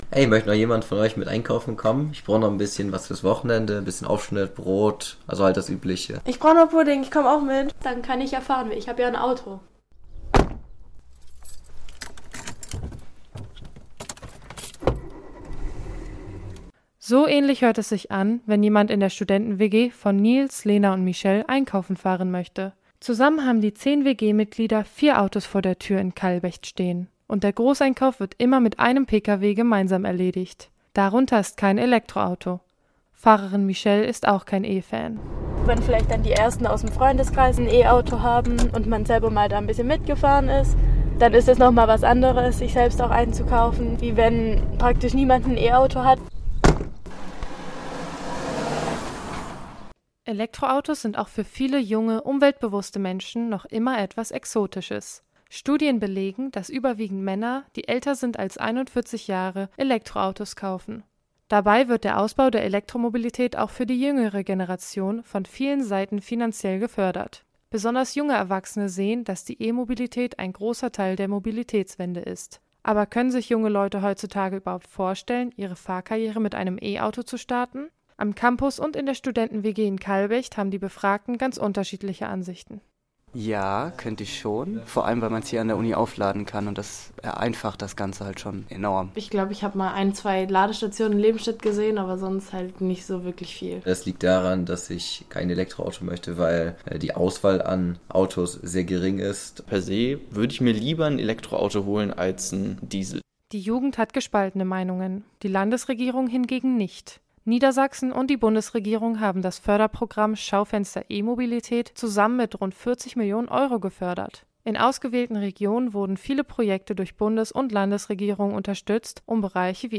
Aber warum braucht die Umsetzung dann so viel länger als erwartet? Campus38 fragt eine Studierenden-WG, einen Fahrschullehrer und zwei Experten: Was halten sie von Elektro-Autos?